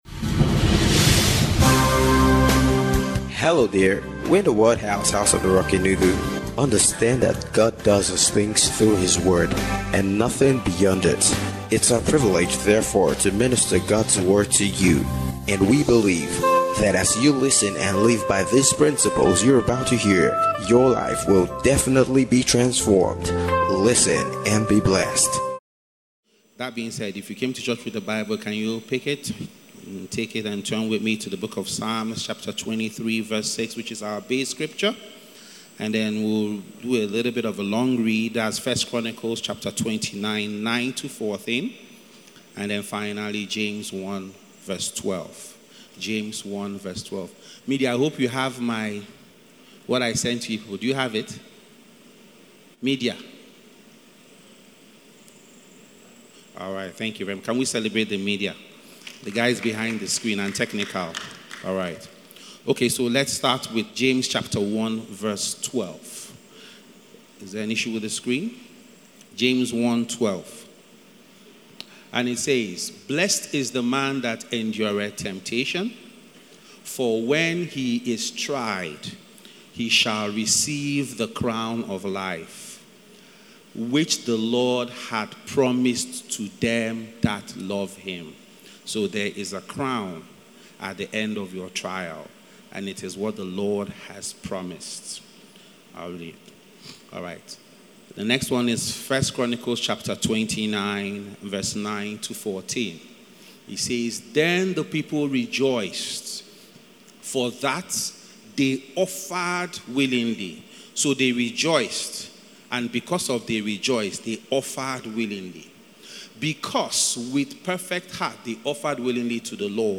Annual Thanksgiving- Empowerment Service